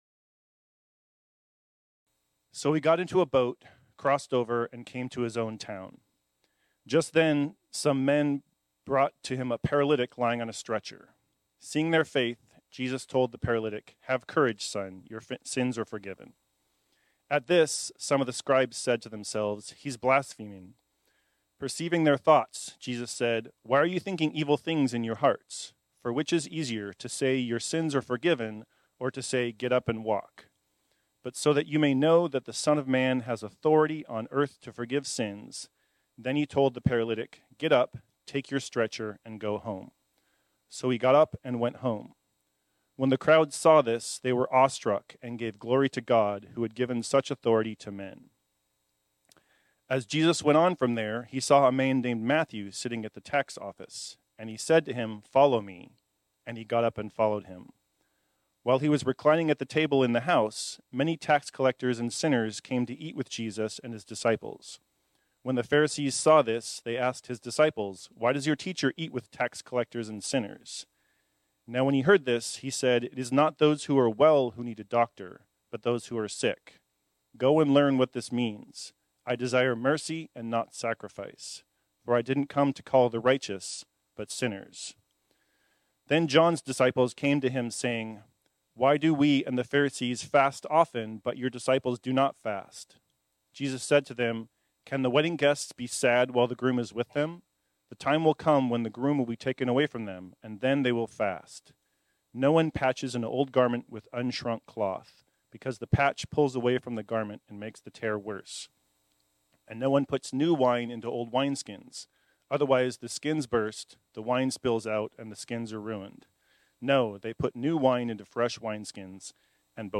This sermon was originally preached on Sunday, April 21, 2024.